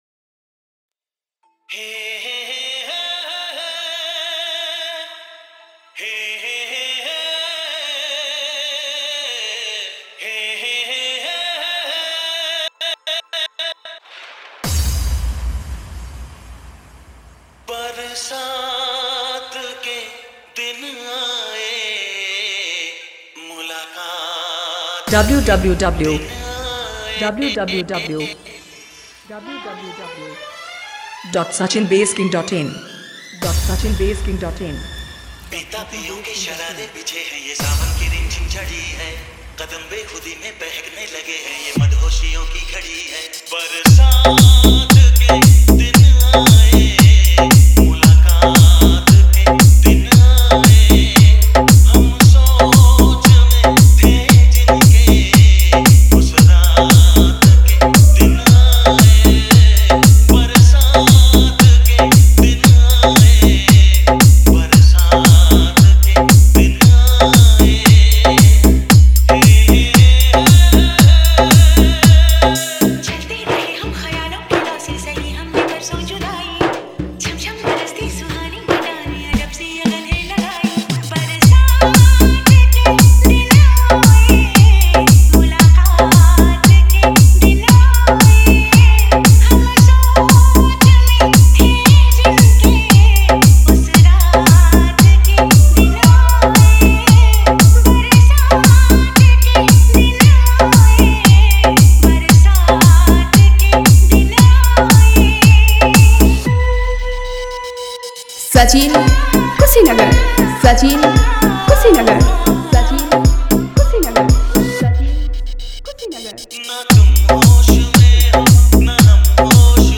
Party Song Dj Remix